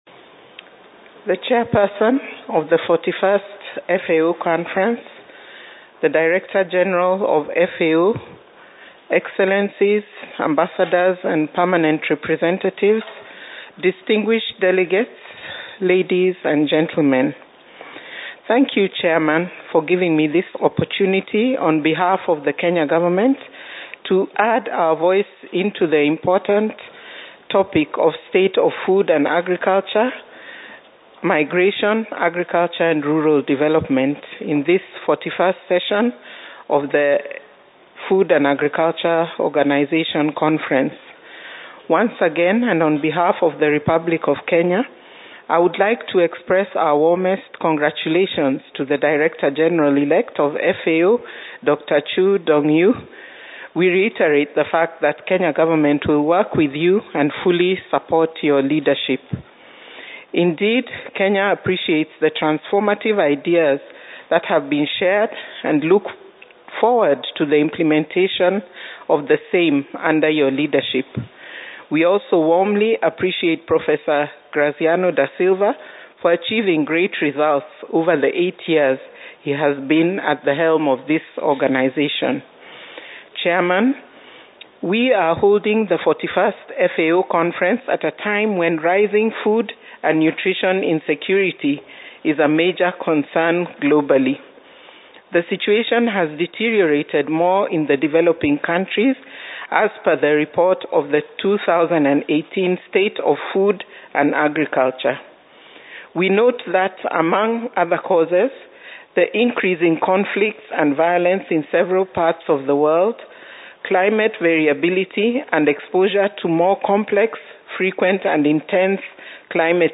Statements by Heads of Delegations under Item 9:
The statements are published as delivered and should be treated as Verbatim Records for item 9.
Ms Ann Onyango Agriculture Secretary, Ministry of Agriculture, Livestock, Fisheries and Irrigation of the Republic of Kenya